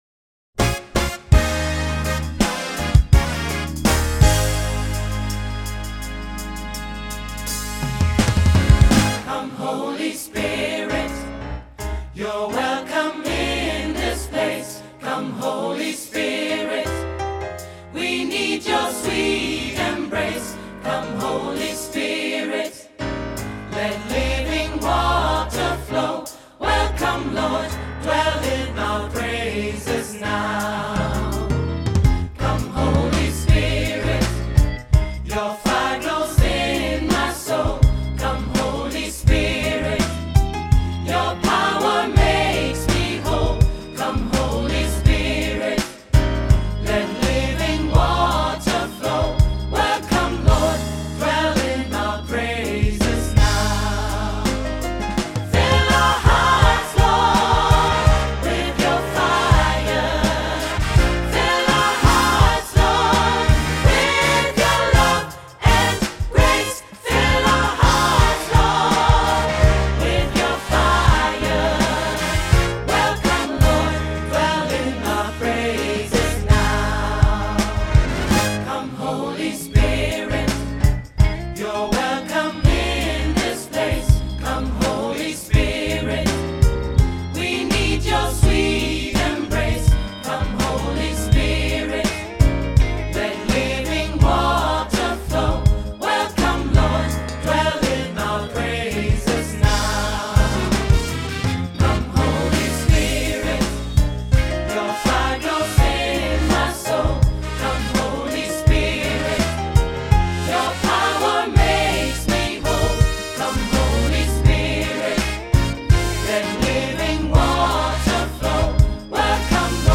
• SAB, auch SSA + Piano
GOSPELNOTEN